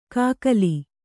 ♪ kākali